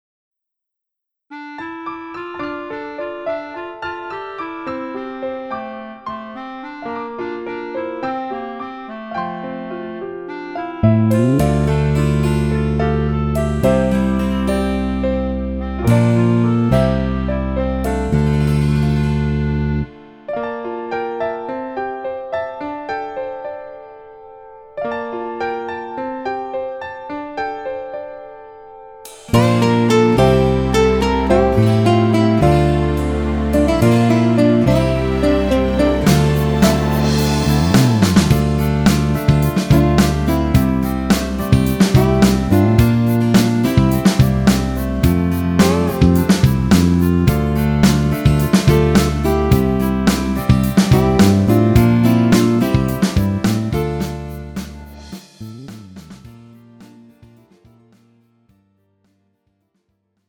음정 원키 4:04
장르 가요 구분 Pro MR